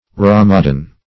Rhamadan \Rham`a*dan"\, n.
rhamadan.mp3